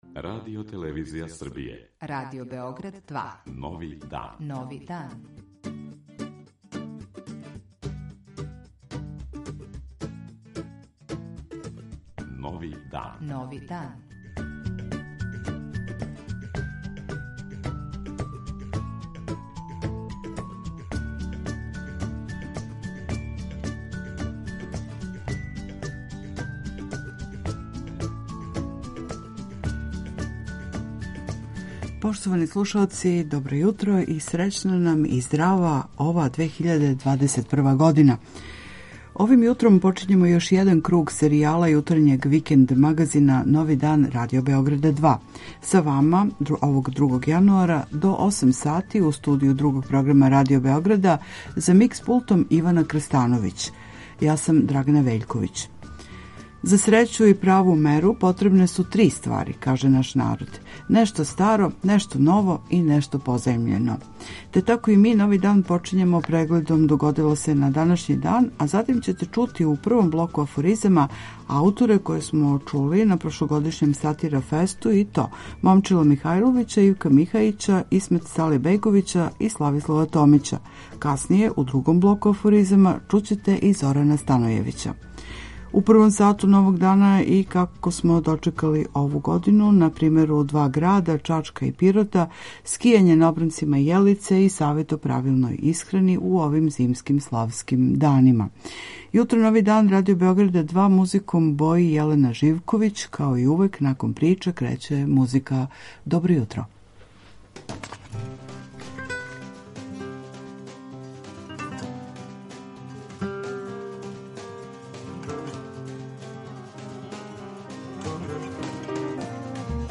Јутарњи викенд програм